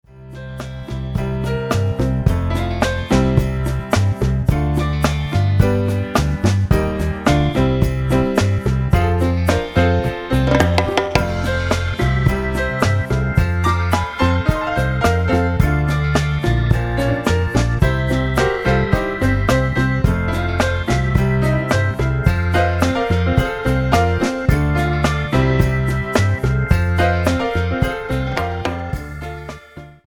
108 BPM
Rolling New Orleans piano fills over a simple Hammond line.